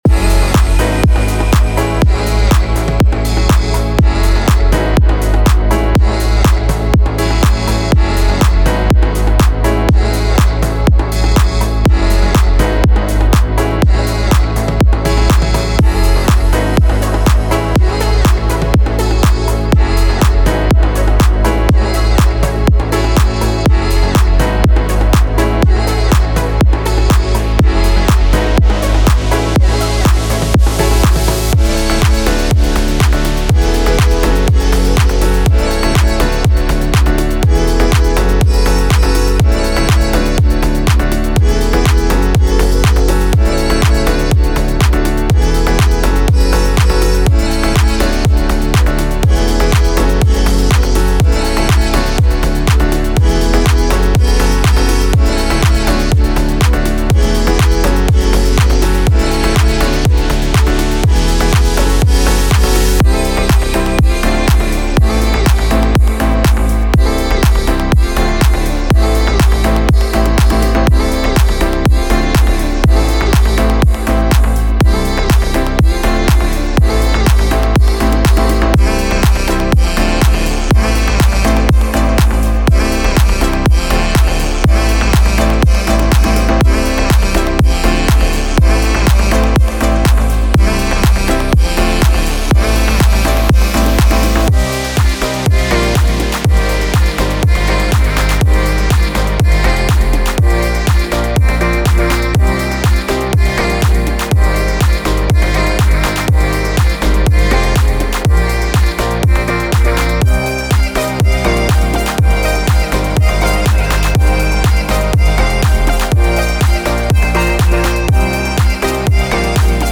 • 15 Bass Loops
• 30 Drum Loops
• 30 Synth Loops
• 10 Vox Loops